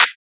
• Trap Clap Single Hit F# Key 30.wav
Royality free hand clap sound - kick tuned to the F# note. Loudest frequency: 4257Hz
trap-clap-single-hit-f-sharp-key-30-Oo2.wav